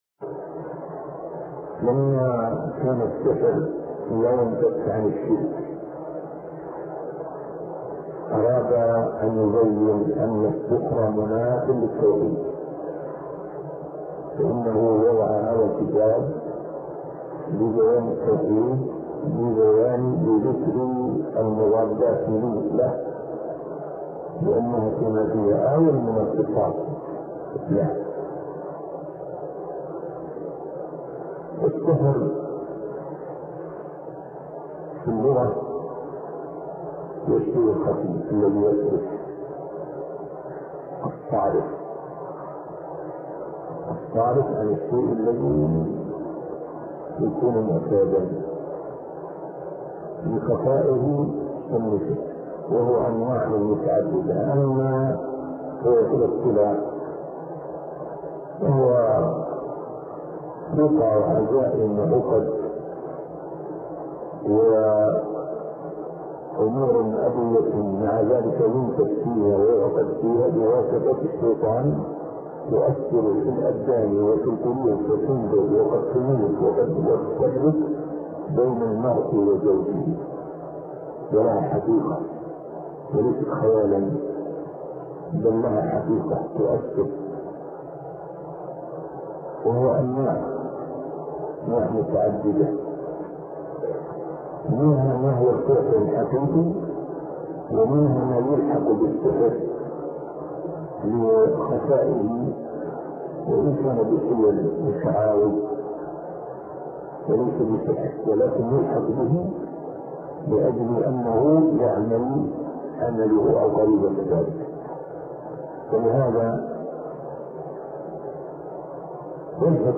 عنوان المادة الدرس ( 73) شرح فتح المجيد شرح كتاب التوحيد تاريخ التحميل الجمعة 16 ديسمبر 2022 مـ حجم المادة 27.41 ميجا بايت عدد الزيارات 282 زيارة عدد مرات الحفظ 140 مرة إستماع المادة حفظ المادة اضف تعليقك أرسل لصديق